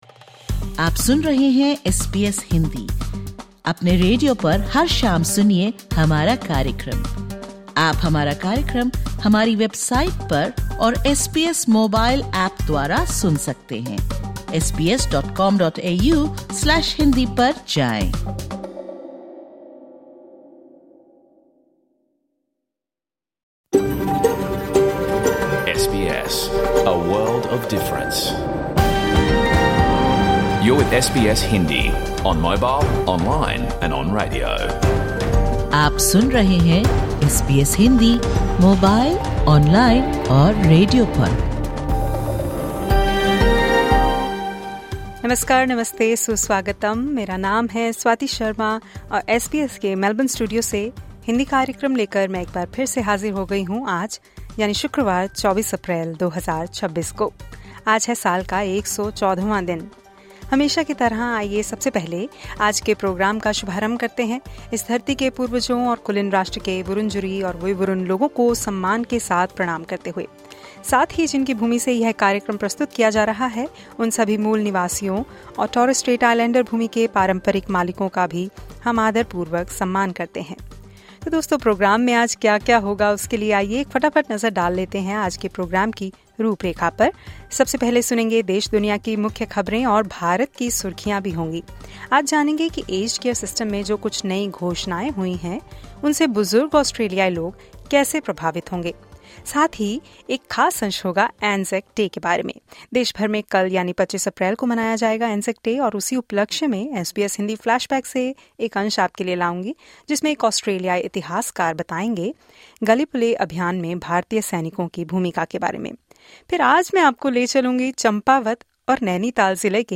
Catch the full radio program of SBS Hindi